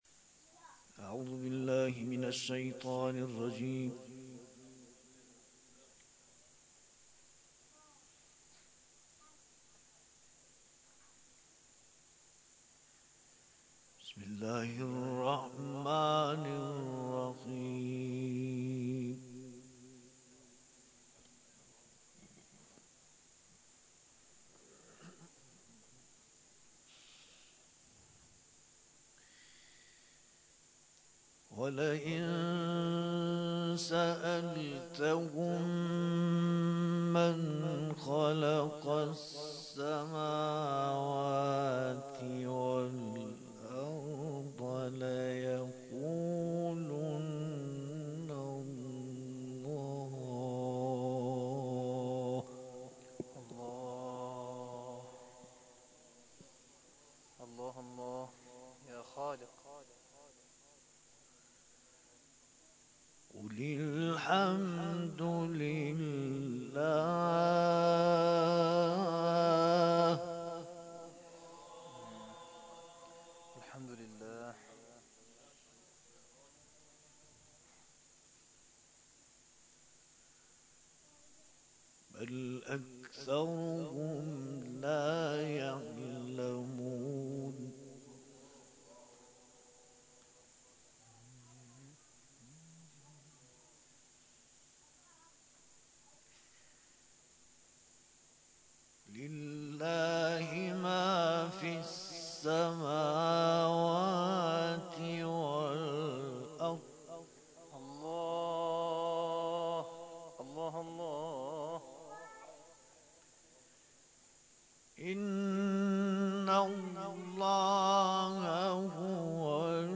جدیدترین تلاوت
مورد تشویق حاضران در جلسه قرار گرفت